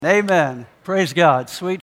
[VIDEO: Skit Guys; Joy]